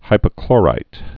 (hīpə-klôrīt)